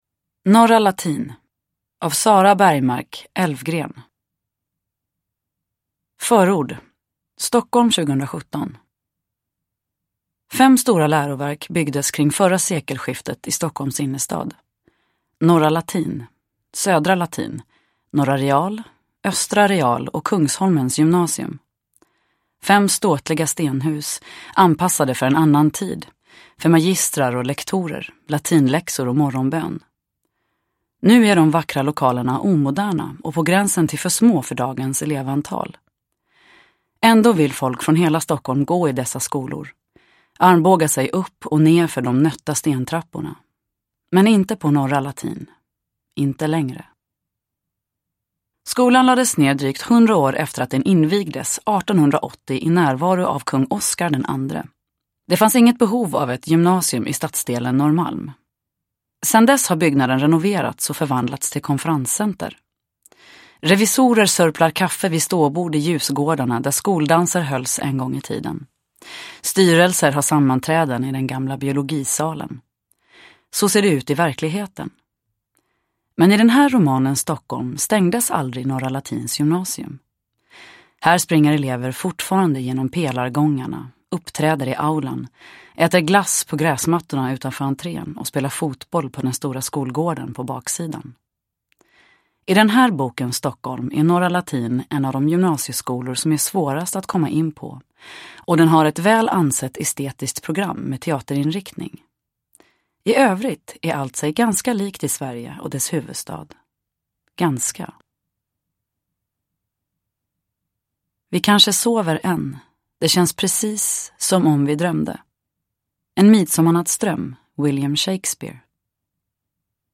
Norra Latin – Ljudbok – Laddas ner
Uppläsare: Nina Zanjani